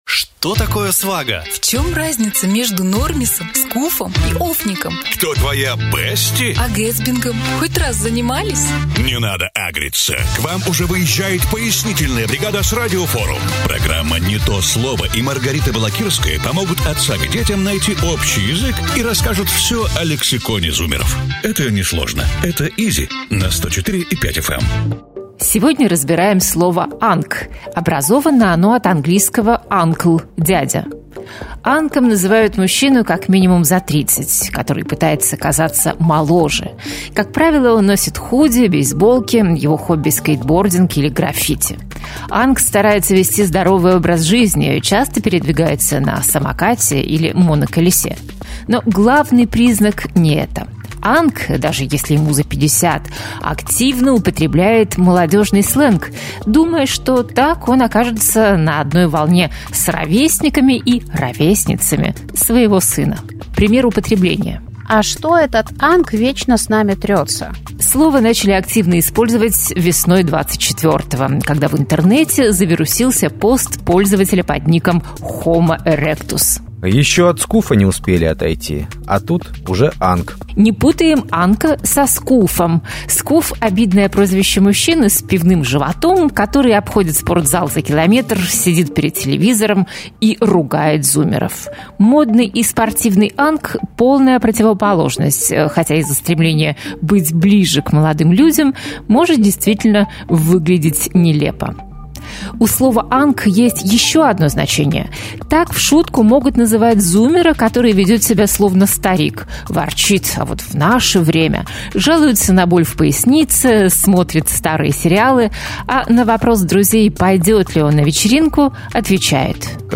Рассказываем всё о лексиконе зумеров в программе «Не то слово». Ведущая